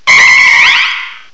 audino_mega.aif